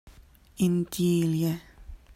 Indillë - in-dyeel-ye - Quenya - ‘sparkling lily’